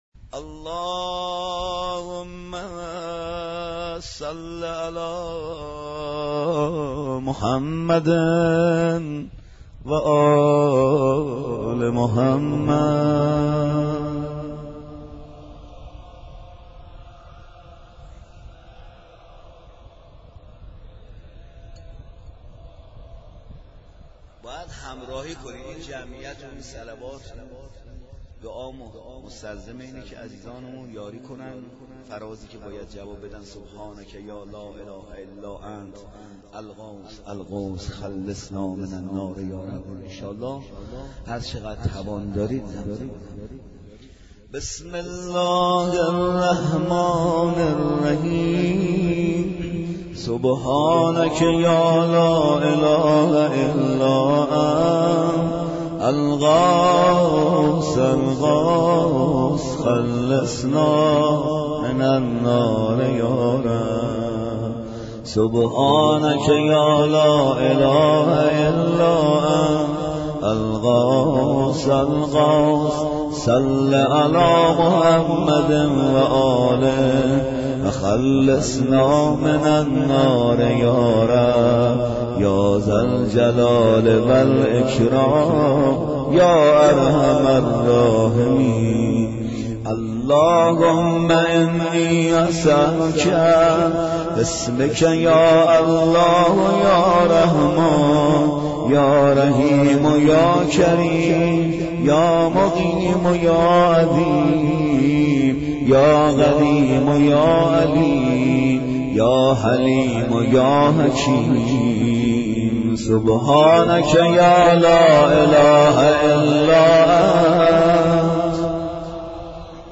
1 0 قرائت دعای جوشن کبیر